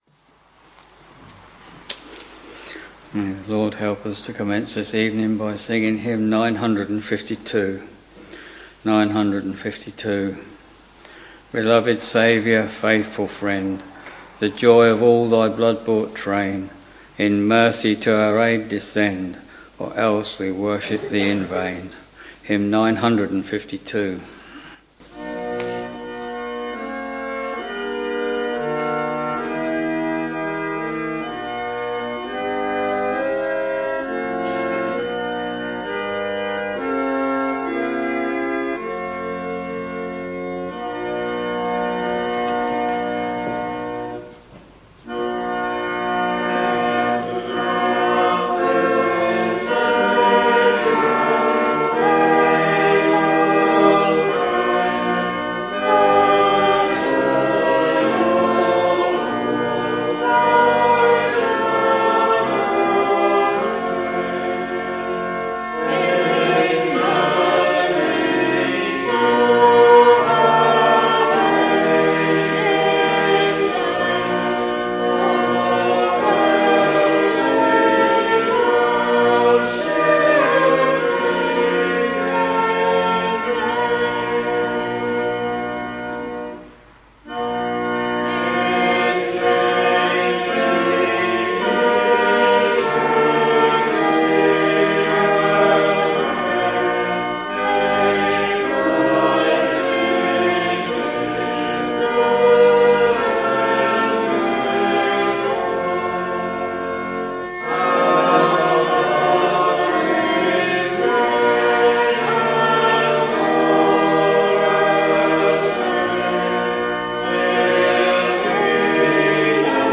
Week Evening Service Preacher